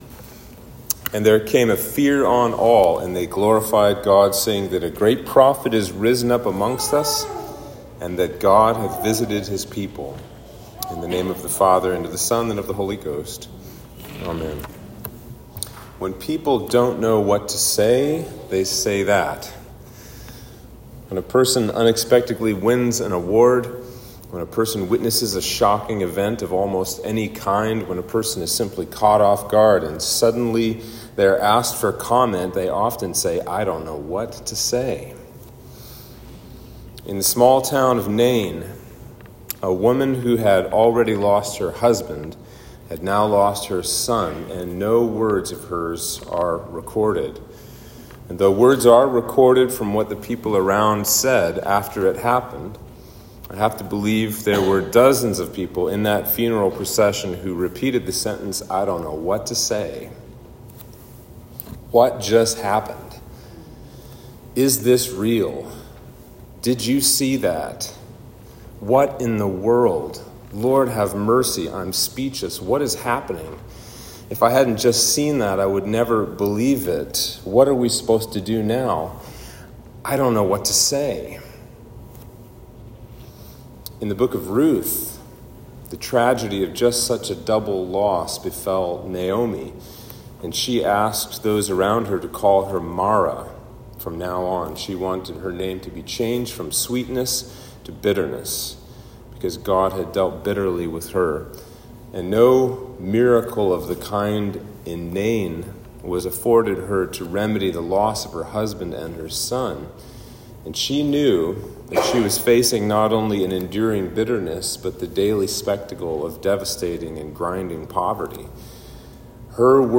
Sermon for Trinity 16